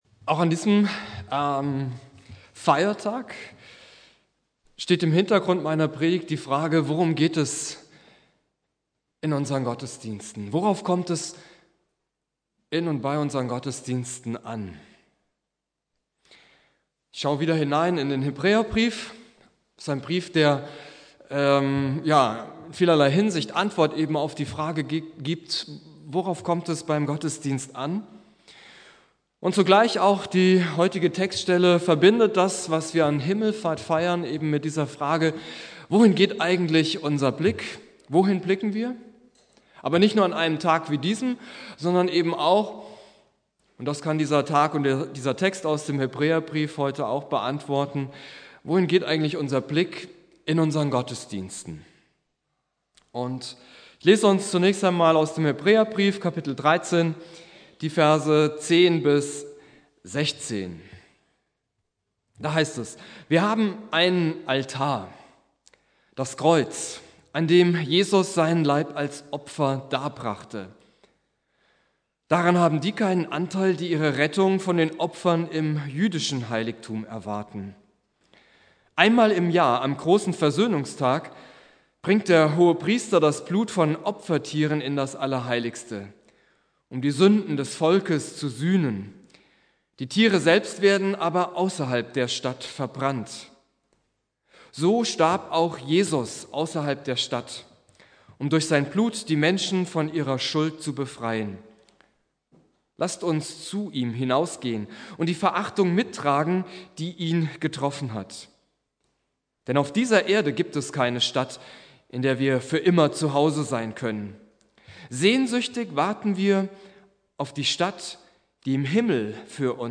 Predigt
Christi Himmelfahrt Prediger